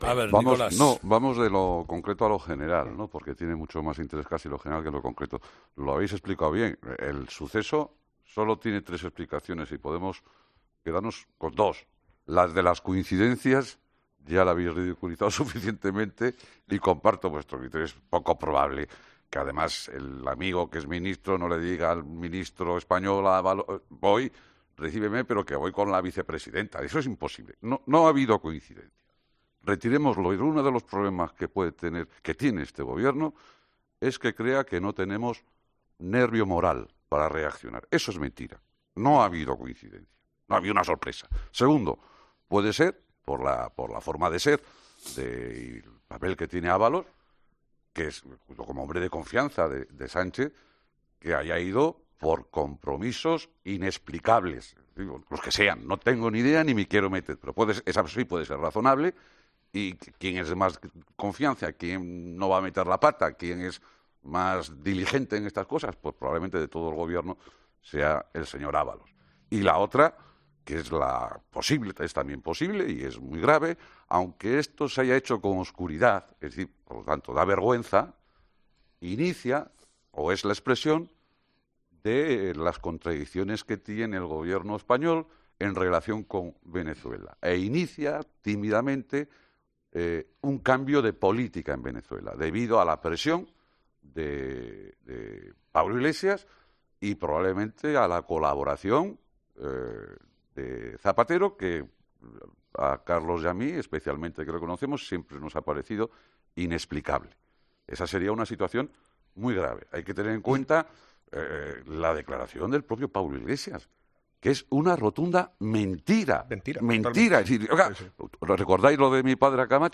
Este hecho y el que Pedro Sánchez haya optado por no recibir en la Moncloa a Juan Guaidó, presidente interino de  Venezuela que se está reuniendo con los grandes mandatarios europeos, hoy mismo va a ser recibido en el Elíseo por Manuel Macrón, y el jueves estuvo reunido con la canciller alemana Angela Merkel han llevado a un socialista de siempre y exsecretario general de los socialistas vascos, Nicolás Redondo Terreros ha hace esta reflexión en 'Herrera en COPE':